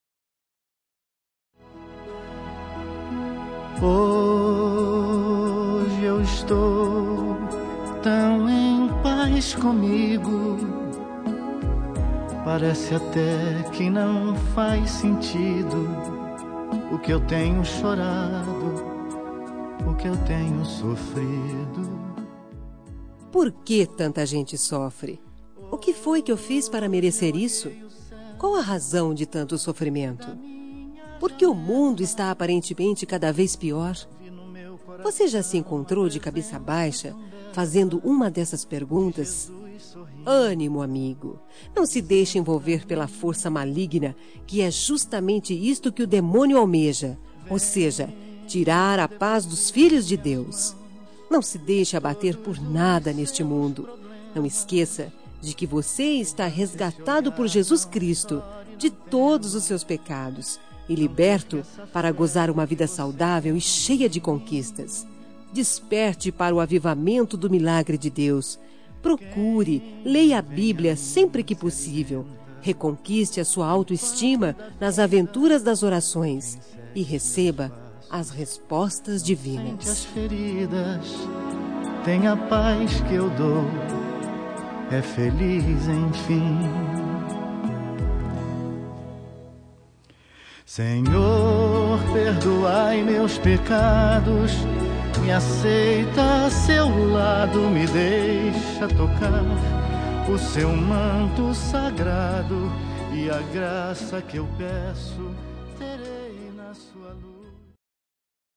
Telemensagem de Otimismo – Voz Feminina – Cód: 4990-1 – Religiosa